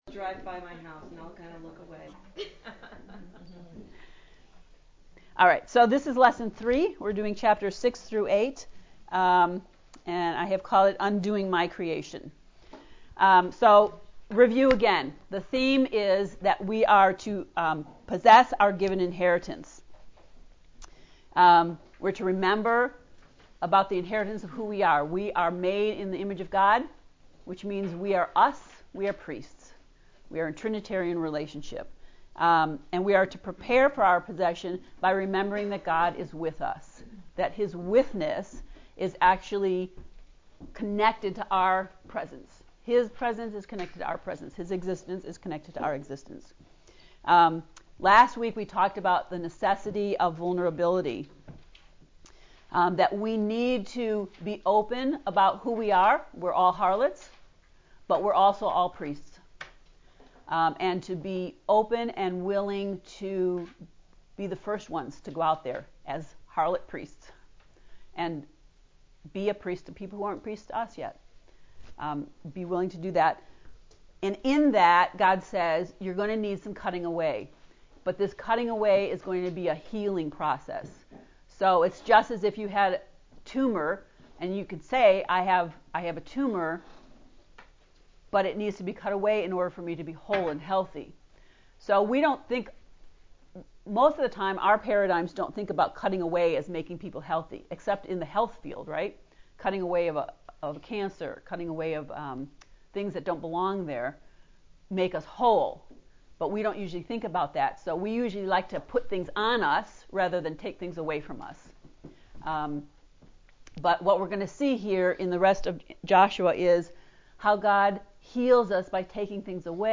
To listen to lesson 3 lecture, “Undoing My Creation”, click below: